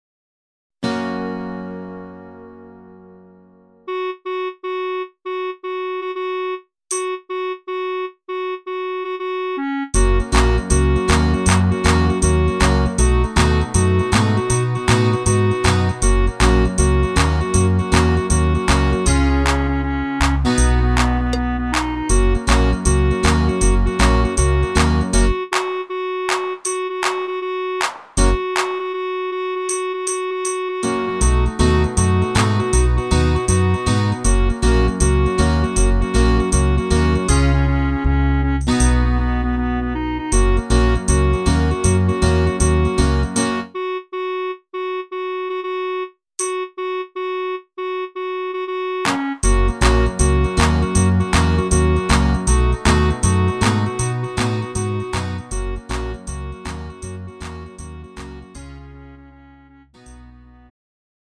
음정 원키
장르 pop 구분 Lite MR